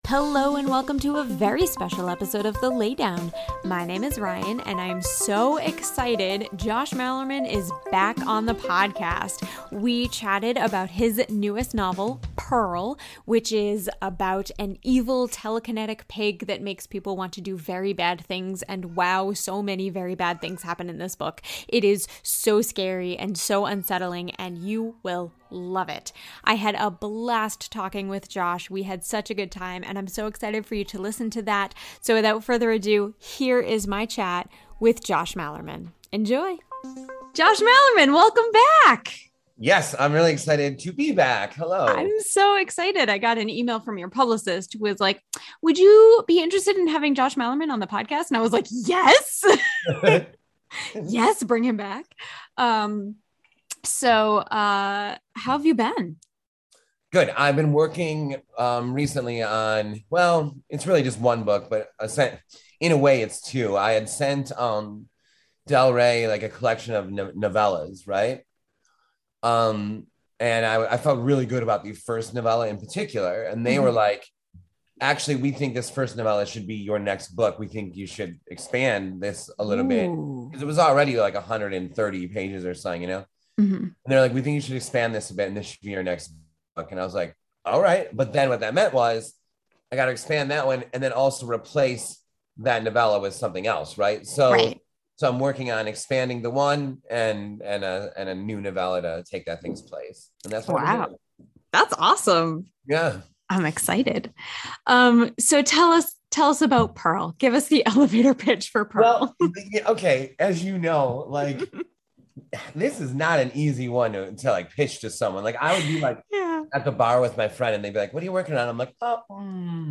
Josh Malerman interview for Pearl!